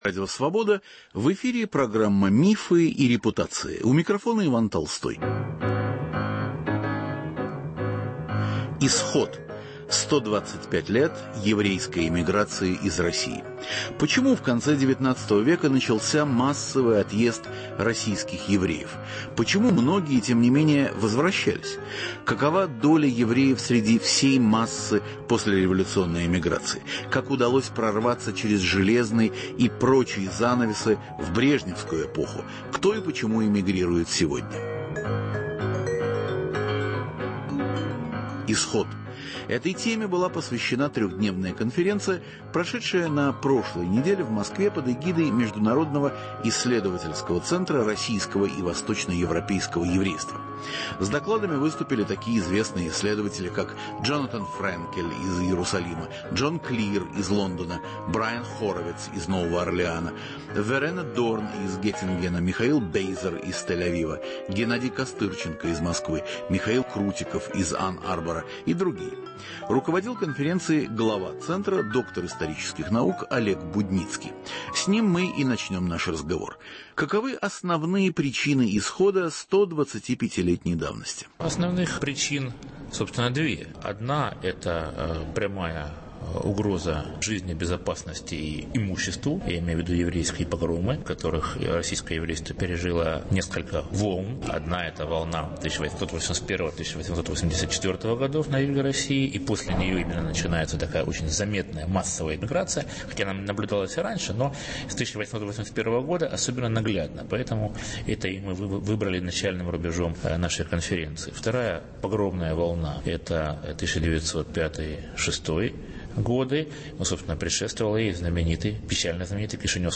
В программе участвуют историки, архивисты, социологи, специалисты по иудаике из разных стран.